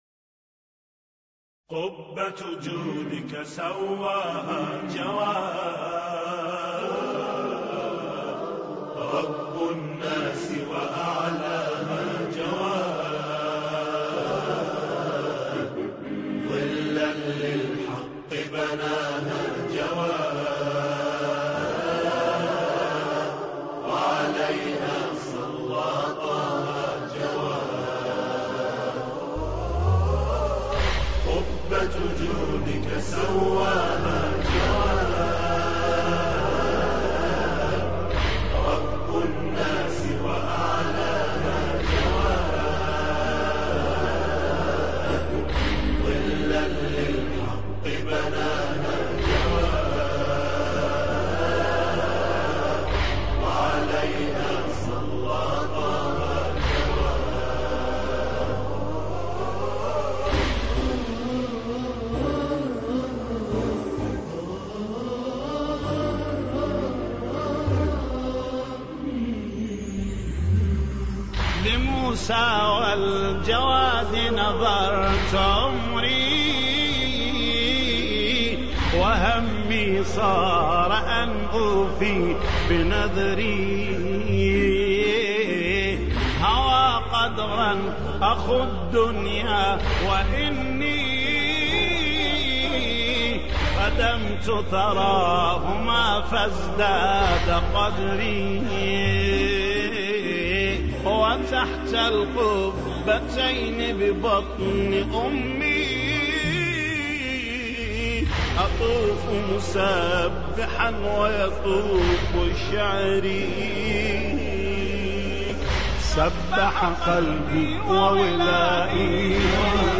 مراثي الامام الجواد (ع)